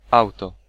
Ääntäminen
UK : IPA : [ə ˈkɑː] US : IPA : /ˈkɑɹ/ UK : IPA : /ˈkɑː/